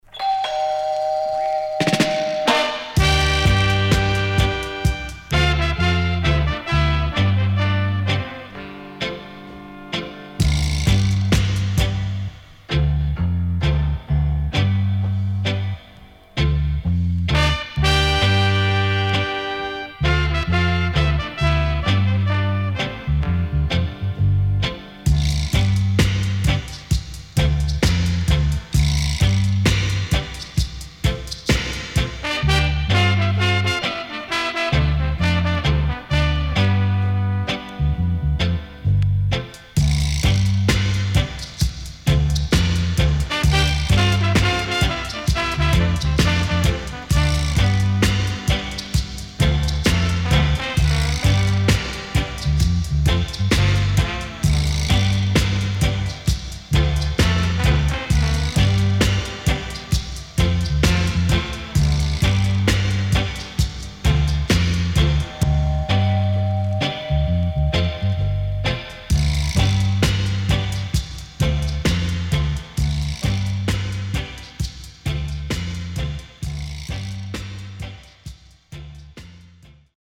HOME > Back Order [VINTAGE DISCO45]  >  INST 70's
SIDE A:少しノイズ入りますが良好です。